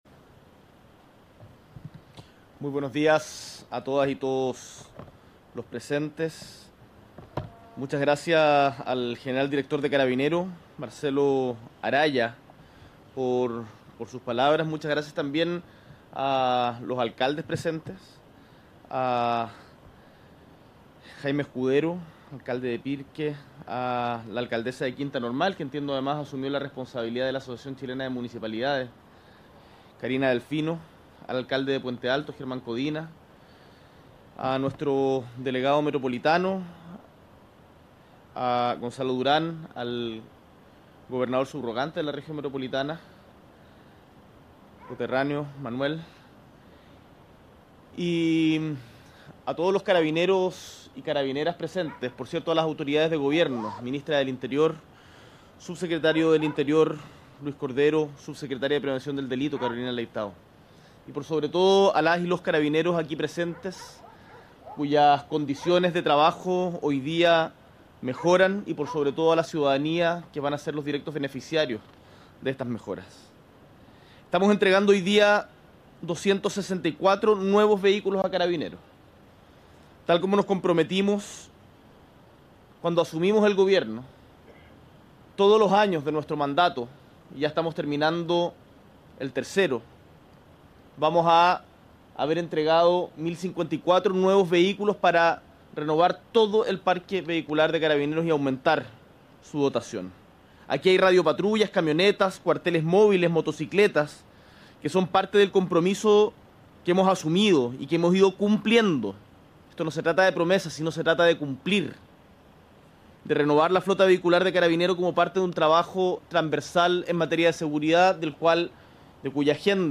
Durante esta jornada, el Presidente de la República, Gabriel Boric, encabezó la segunda entrega de vehículos policiales, en el marco del Plan Nacional contra el Crimen Organizado.